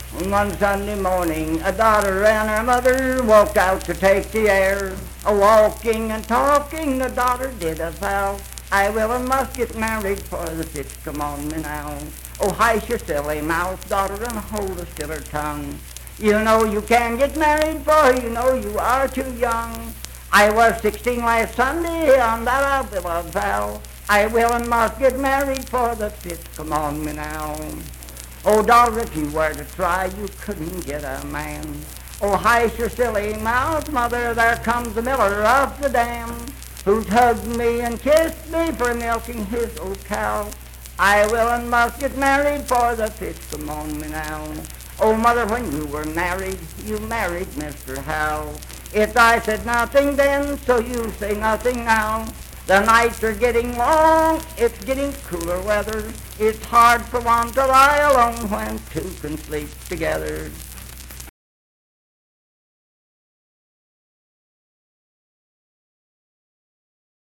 Unaccompanied vocal music performance
Marriage and Marital Relations, Bawdy Songs
Voice (sung)